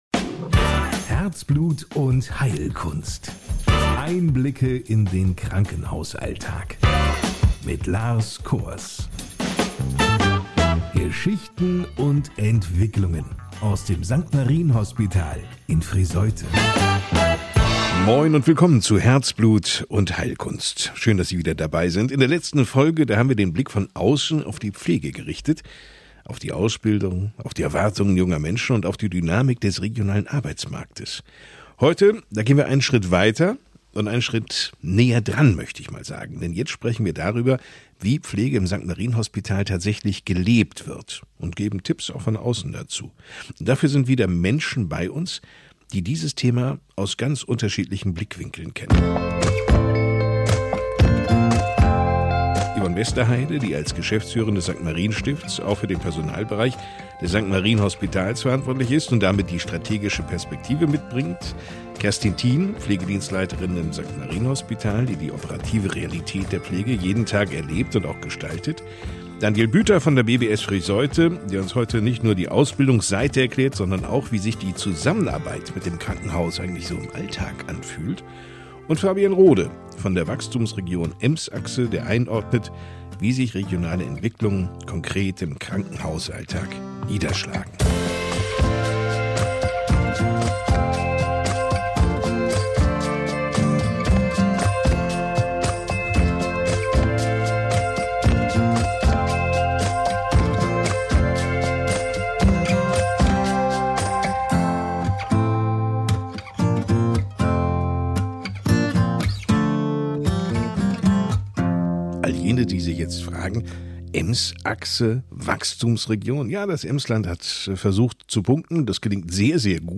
Beschreibung vor 2 Wochen In dieser Podcast-Folge von "Herzblut und Heilkunst" erhalten die Hörer einen authentischen Einblick in den Pflegealltag des St.-Marien-Hospitals. Vier Gäste aus unterschiedlichen Bereichen beleuchten, wie Pflegekräfte zwischen Verantwortung, Belastung und Teamgeist ihren Dienst meistern, was gute Führung ausmacht und welche Trends die Branche in Zukunft prägen werden.